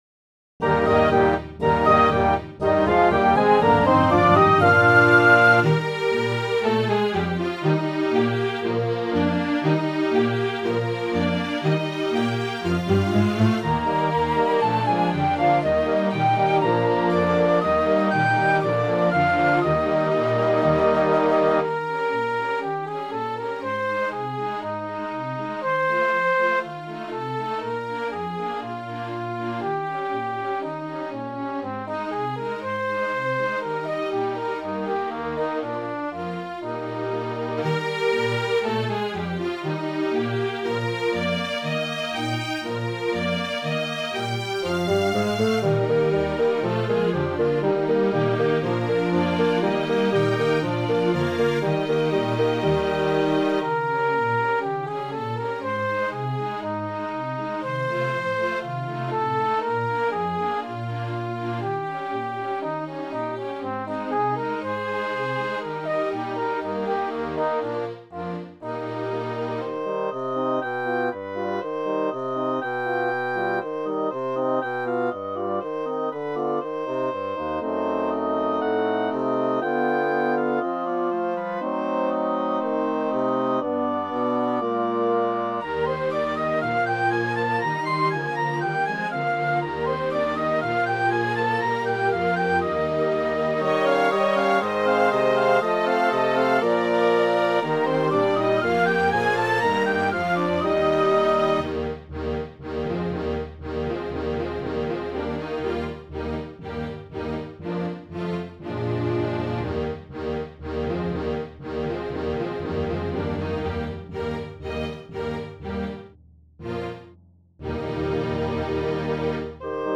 Pied Piper    (Ballet)
"Lantzville Virtual Orchestra"
17-07-Street-Scene.wav